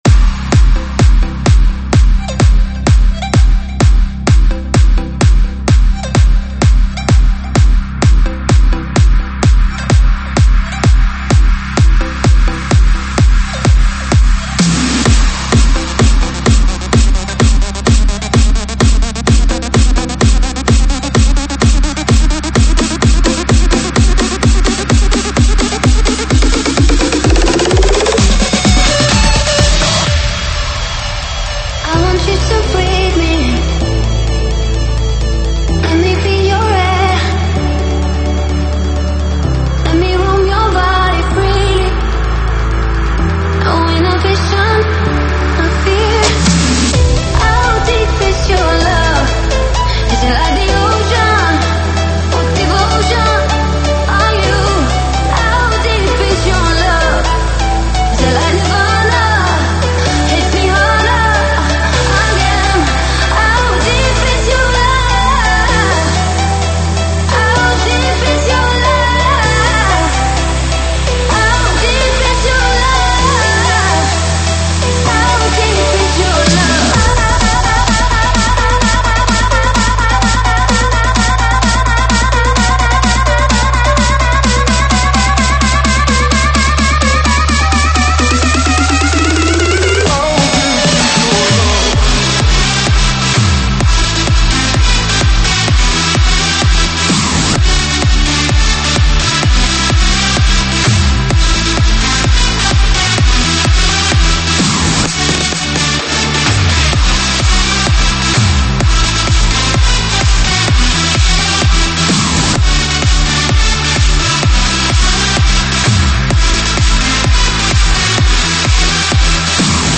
舞曲类别：英文舞曲